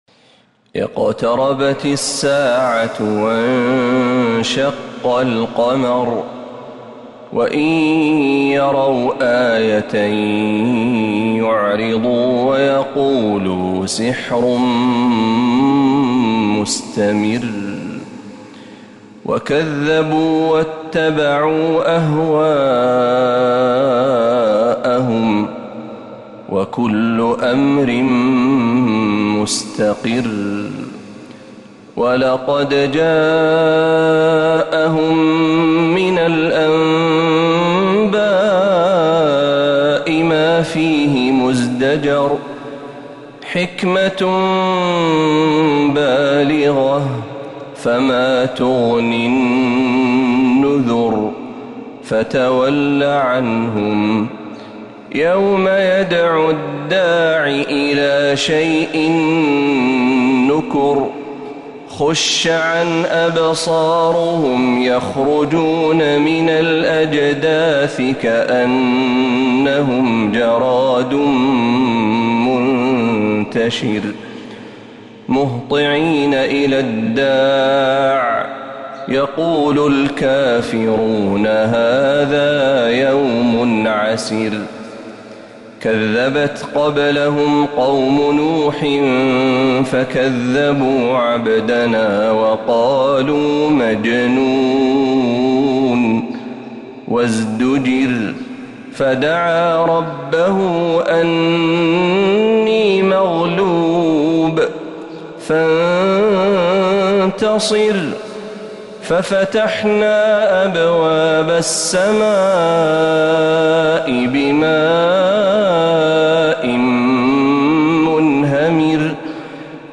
سورة القمر | رجب 1447هـ > السور المكتملة للشيخ محمد برهجي من الحرم النبوي 🕌 > السور المكتملة 🕌 > المزيد - تلاوات الحرمين